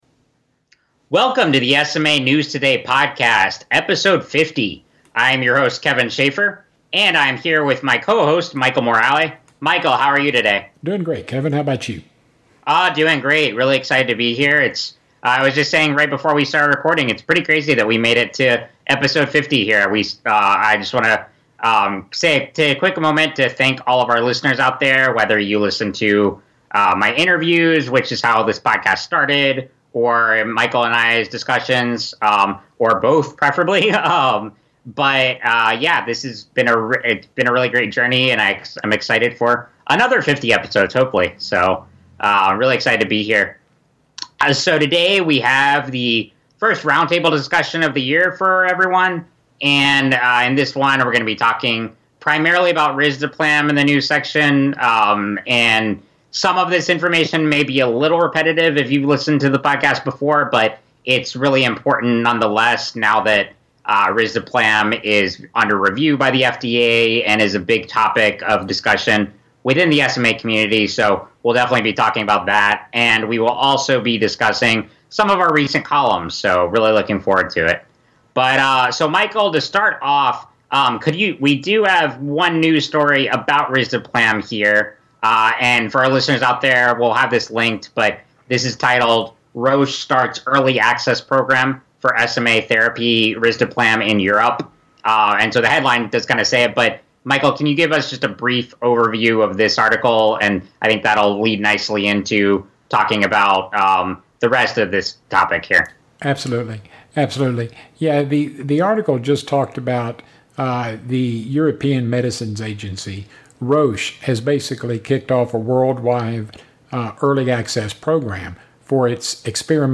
#50 - Roundtable Discussion - January 2020